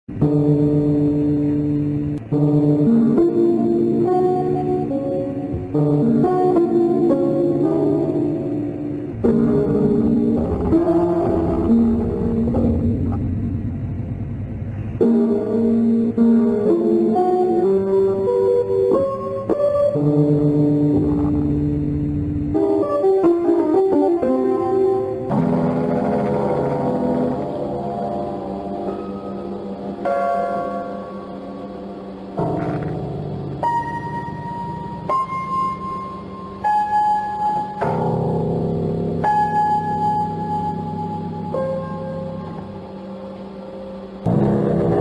Download Piano Banging sound effect for free.
Piano Banging